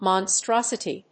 音節mon・stros・i・ty 発音記号・読み方
/mɑnstrάsəṭi(米国英語), mɔnstrˈɔsəṭi(英国英語)/